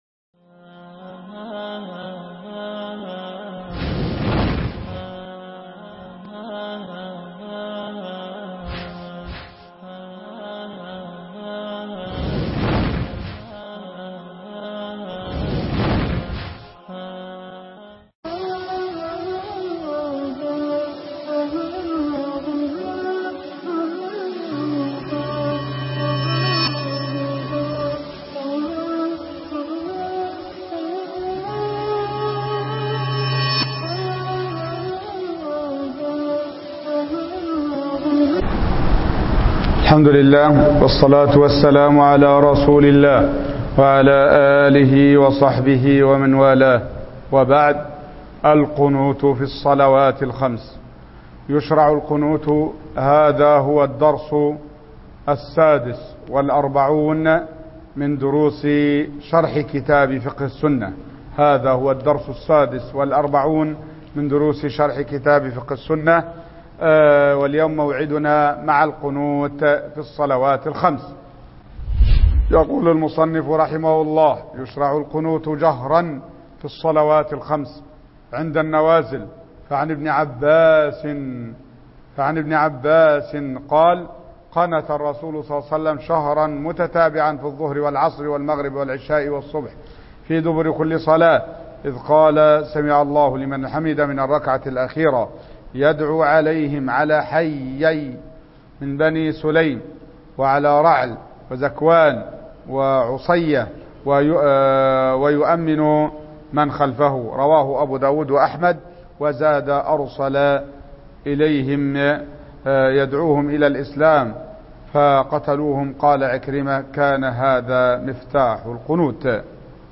شرح كتاب فقه السنة الدرس 46